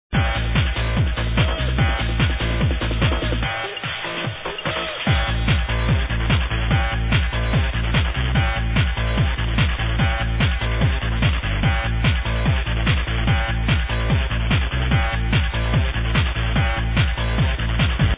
Arrow nu-nrg ID